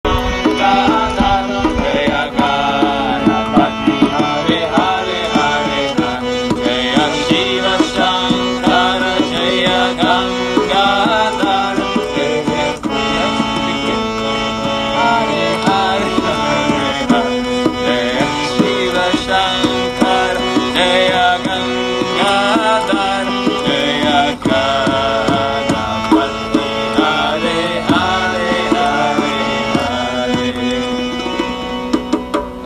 Chants
baba-birthday-chant-2018.wav